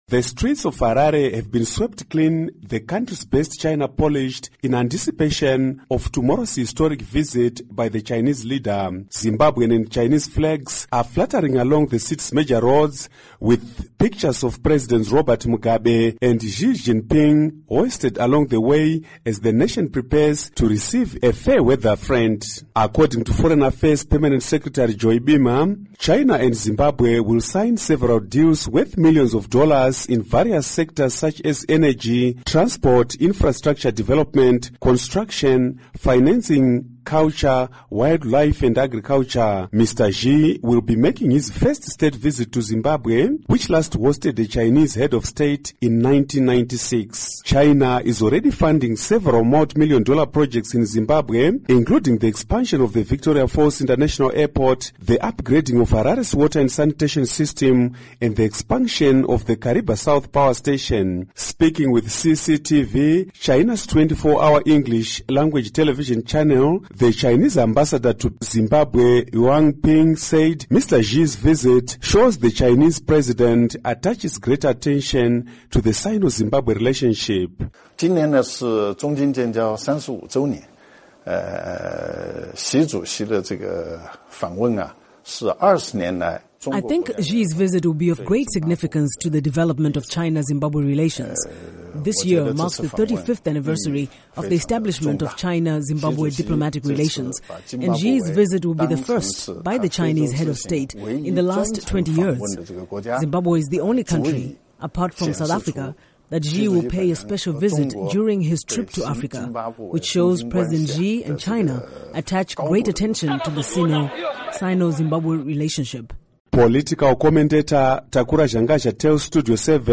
Report on Chinese Premier's Visit